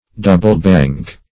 Meaning of double-bank. double-bank synonyms, pronunciation, spelling and more from Free Dictionary.